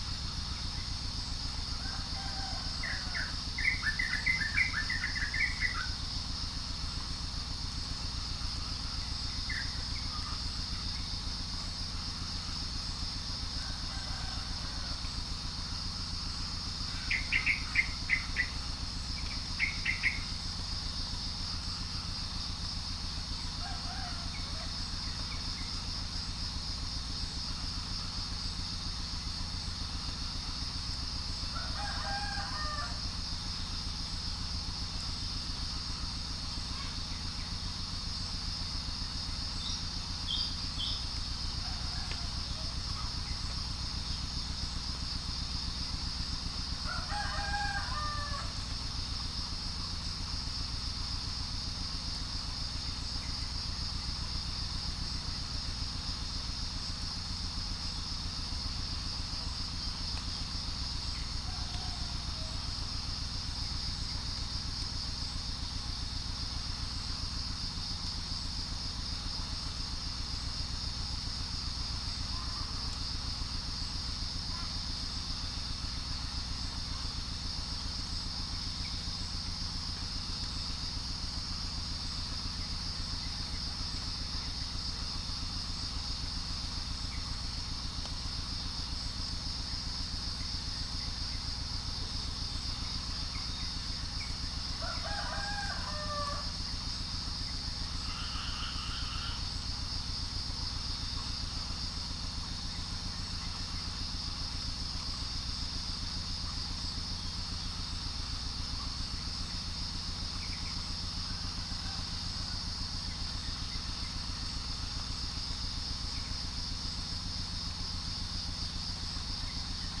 Spilopelia chinensis
Gallus gallus
Pycnonotus goiavier
unknown bird
Orthotomus ruficeps
Prinia familiaris